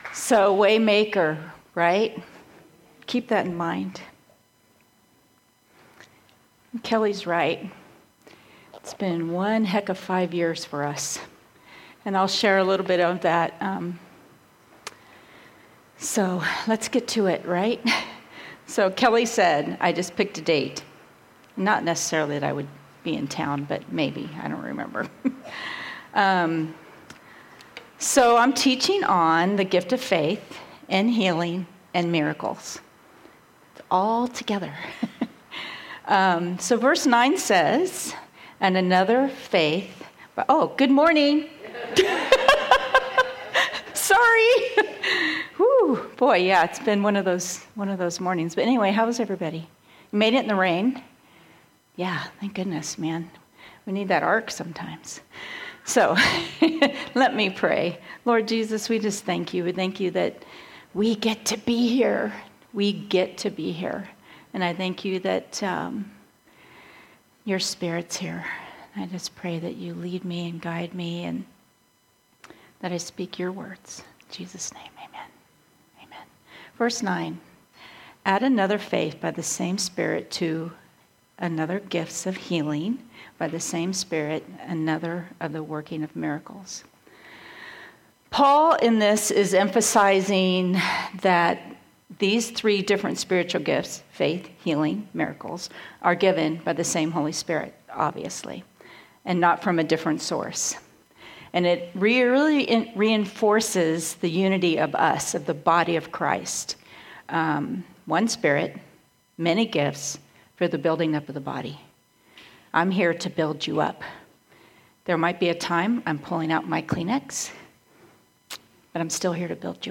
A message from the series "Women of the Word."